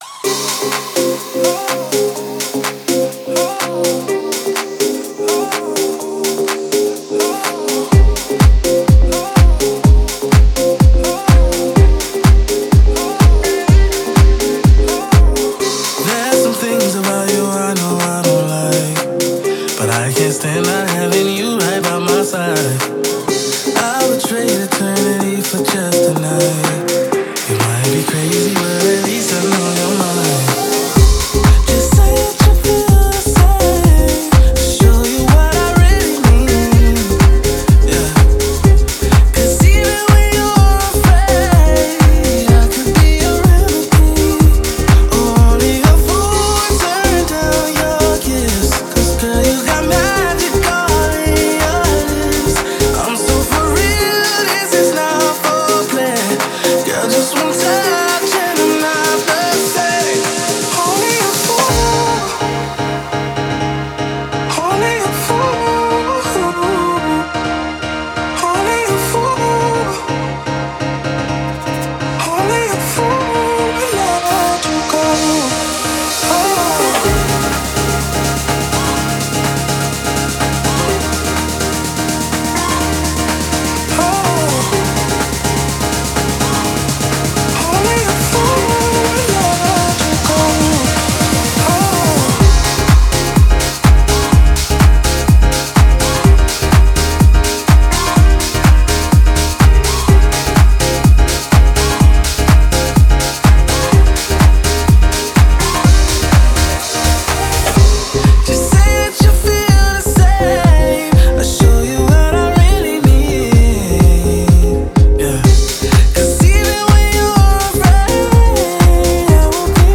динамичная электронная композиция